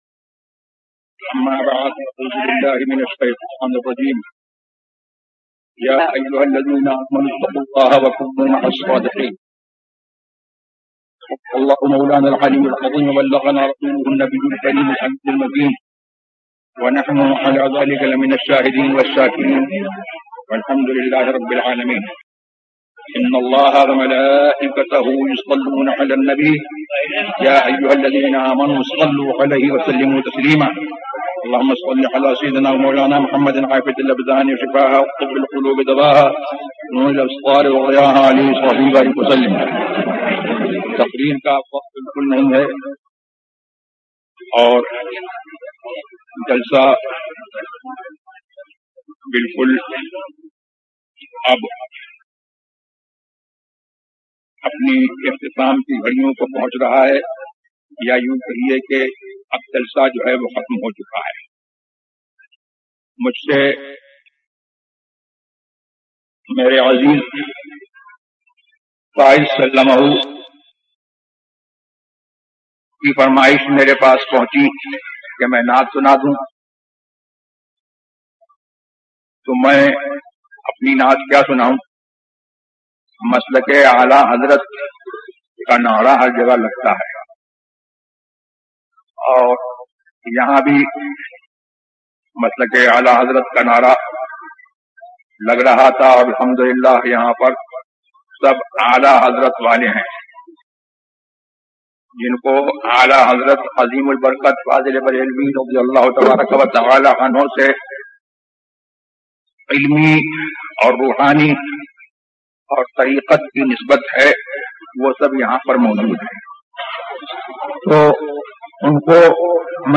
سر سوئے روضا جھکا پھر تجھ کو کیا ZiaeTaiba Audio میڈیا کی معلومات نام سر سوئے روضا جھکا پھر تجھ کو کیا موضوع تقاریر آواز تاج الشریعہ مفتی اختر رضا خان ازہری زبان اُردو کل نتائج 925 قسم آڈیو ڈاؤن لوڈ MP 3 ڈاؤن لوڈ MP 4 متعلقہ تجویزوآراء